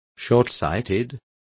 Complete with pronunciation of the translation of shortsighted.